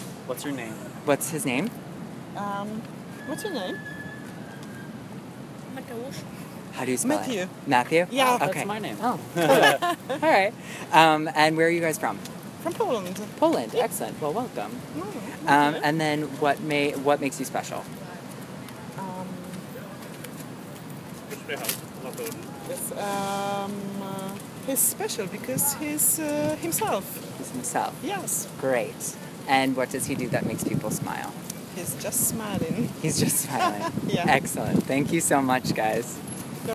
SMILE PROJECT is an online gallery that showcases a variety of street interviews focused on two questions 1.) what gifts do you have that uplift the human spirit? and 2.) what do you do that makes people smile?